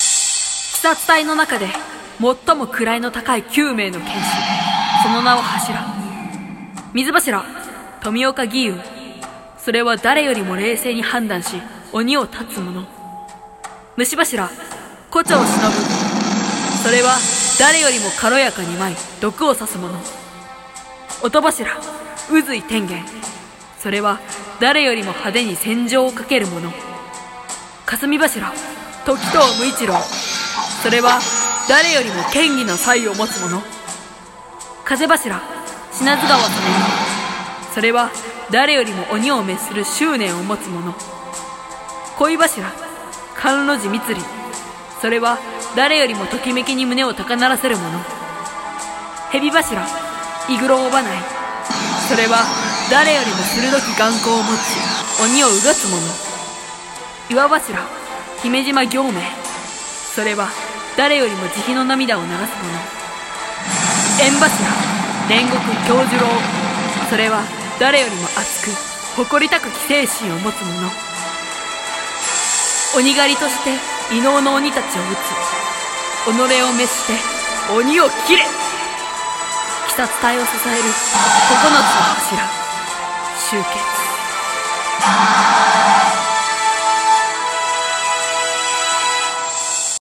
【声劇台本】鬼滅の刃！ナレーション風